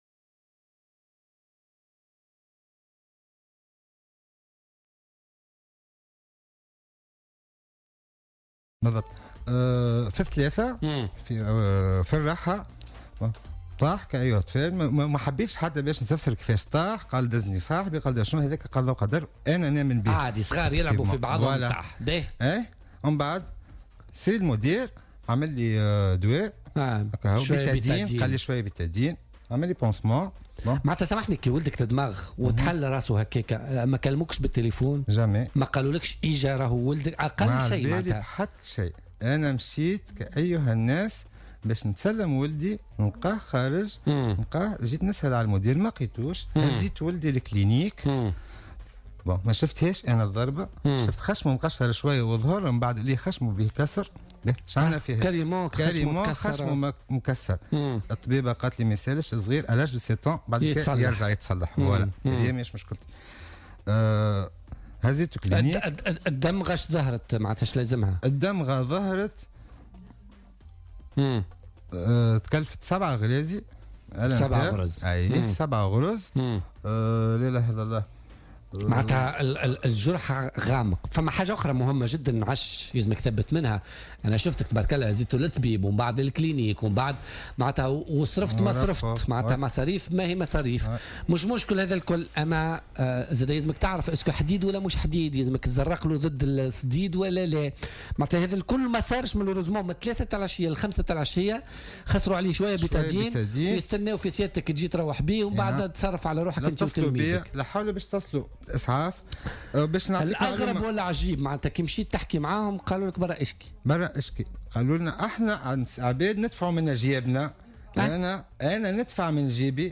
تدخل مواطن اليوم الثلاثاء على الجوهرة "اف ام" في برنامج بولتيكا للحديث عن وضعية ابنه الذي أصيب أثناء وجوده في مدرسته متهما الإطار التربوي والمدير خاصة بإهمال ابنه والتقصير معه وعدم الإسراع في اسعافه والاكتفاء بإسعافات خفيفة .